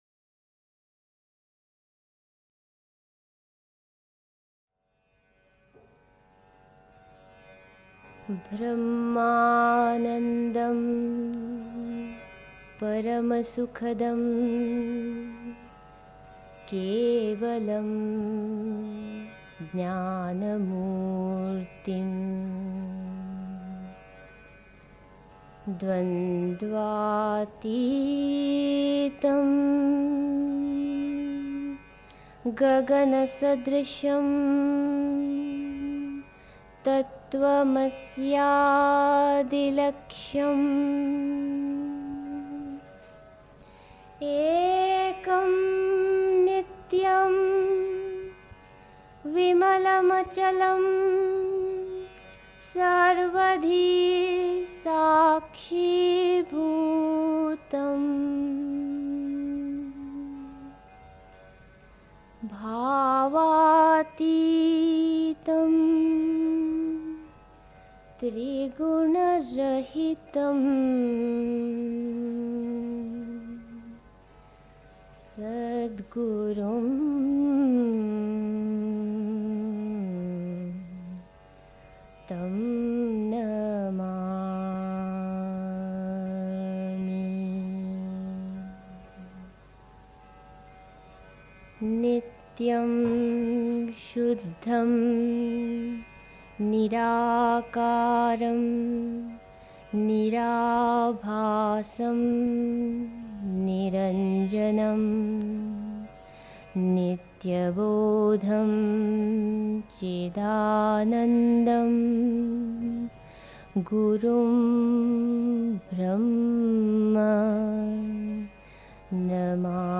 1. Einstimmung mit Musik. 2. Das Ziel des Yoga (Sri Aurobindo, CWSA Vol. 29, p. 21) 3. Zwölf Minuten Stille.